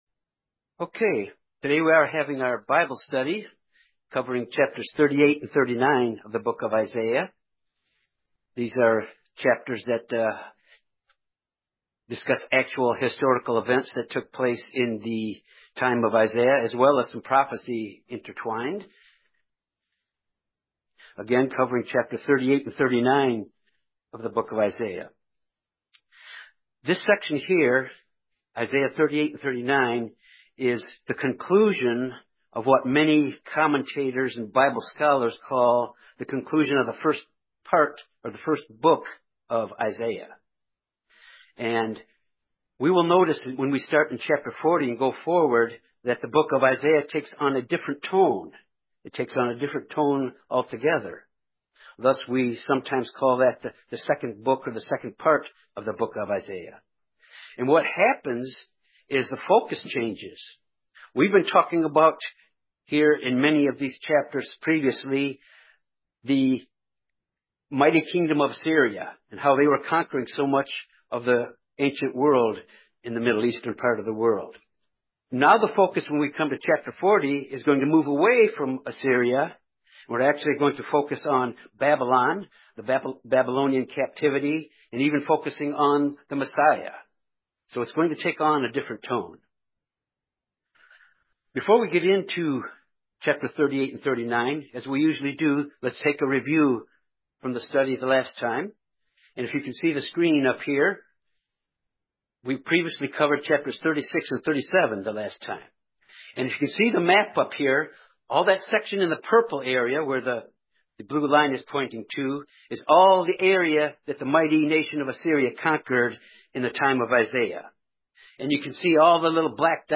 In this Bible study of Isaiah 38-39, Hezekiah was faced with a life-threatening trial.